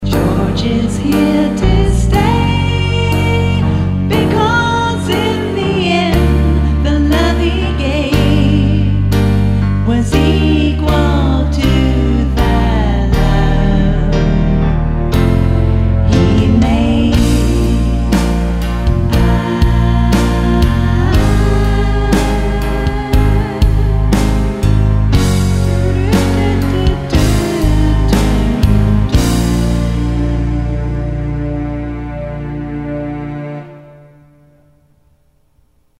Original Music Samples With Vocals